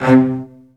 CELLOS.DN3-R.wav